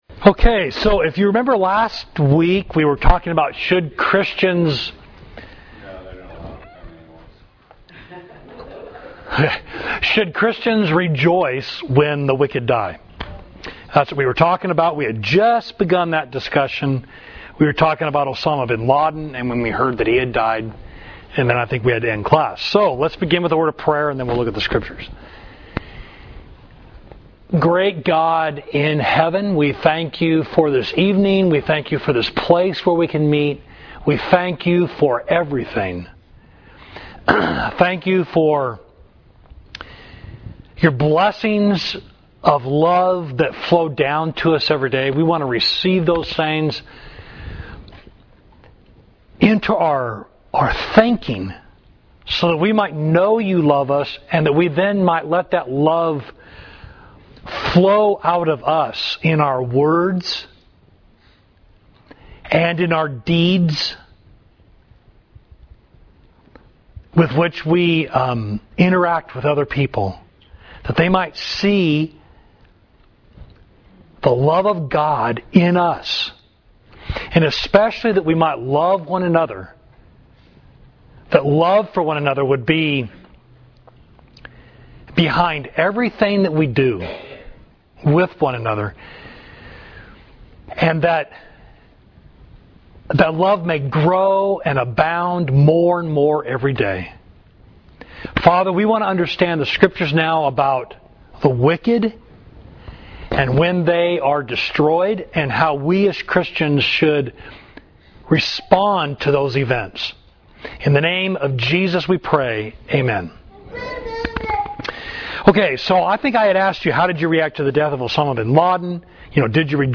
Class: Should Christians Rejoice When the Wicked Die?